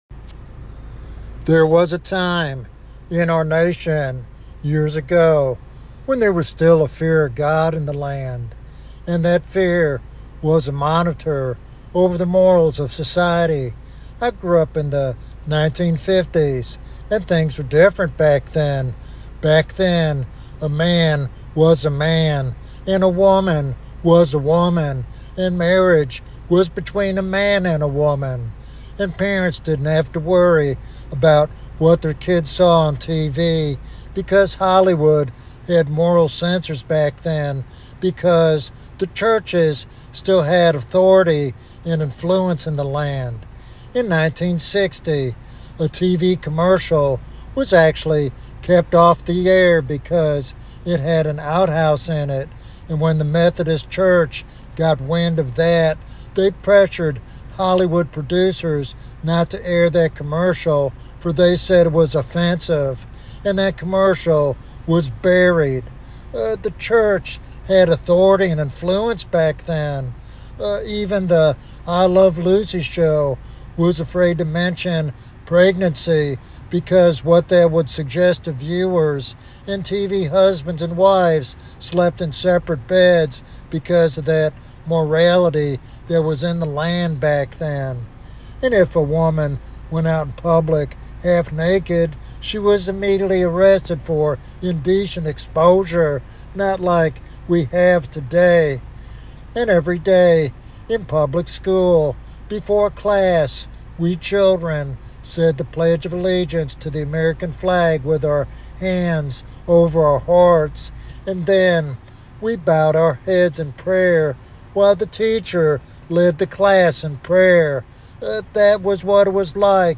This sermon is a passionate call to renew the church's influence and impact on the nation.